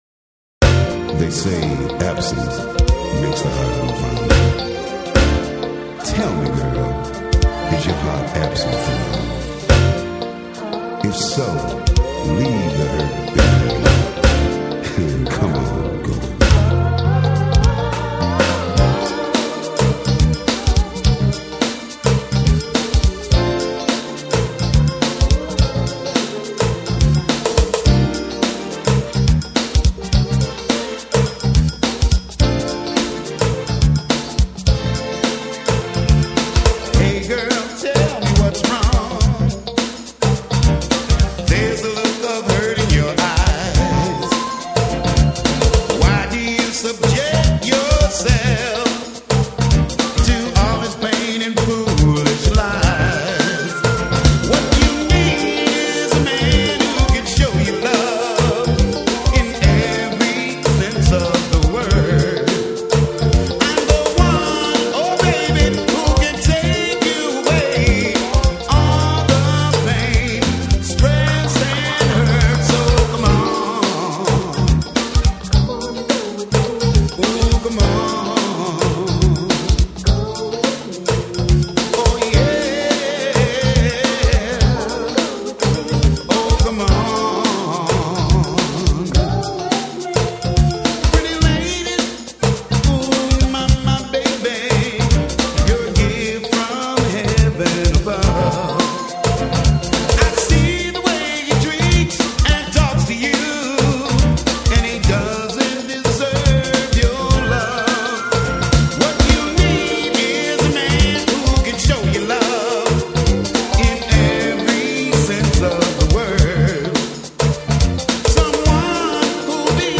classic rock, disco-era and pop songs